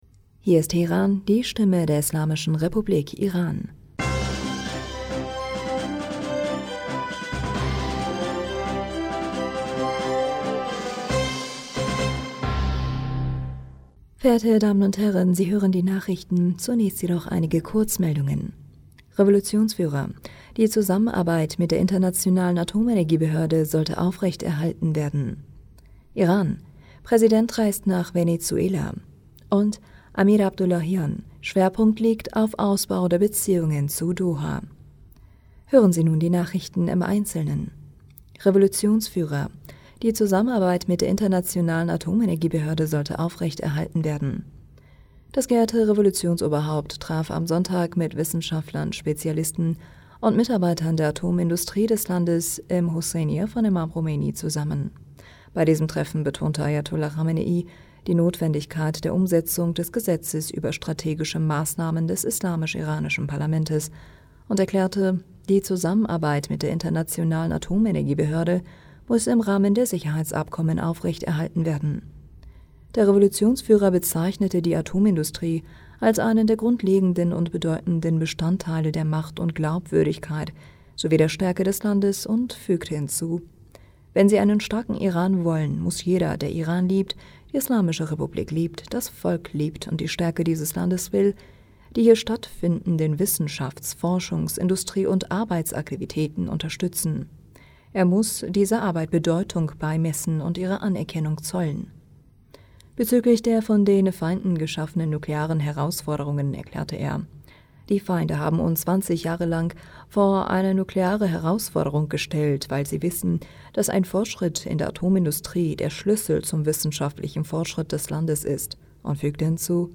Nachrichten vom 12. Juni 2023